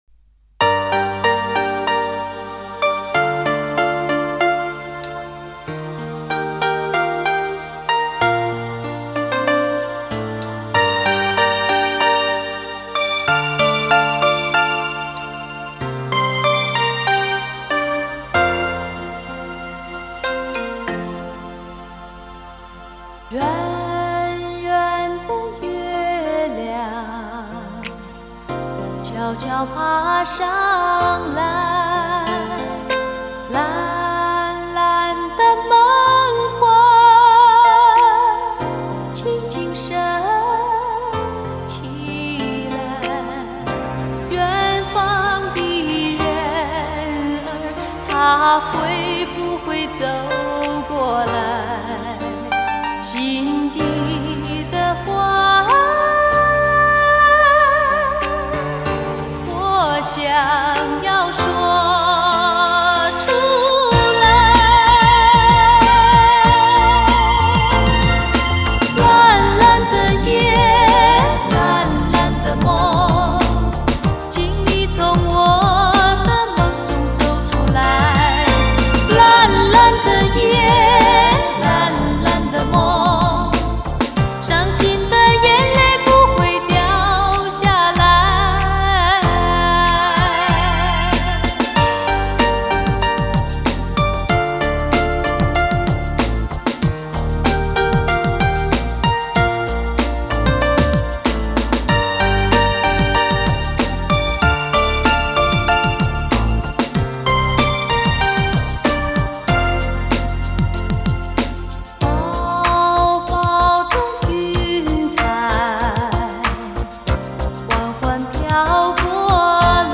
影响世界的三大音响专利技术之一的SRS编码和黑胶碟的完美结合，是汽车一族的唯一首选在有限的空间实现最好的音效。